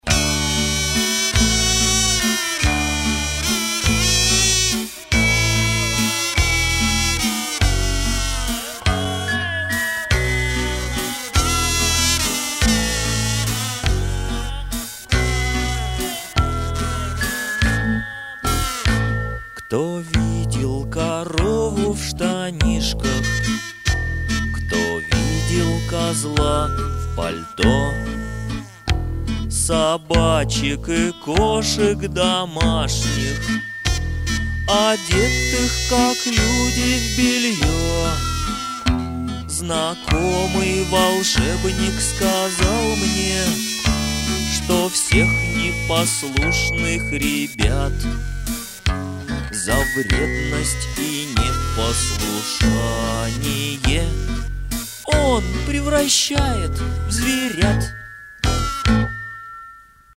• Качество: Хорошее
• Категория: Детские песни